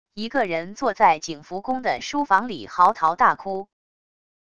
一个人坐在景福宫的书房里嚎啕大哭wav音频